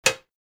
دانلود صدای موس 27 از ساعد نیوز با لینک مستقیم و کیفیت بالا
جلوه های صوتی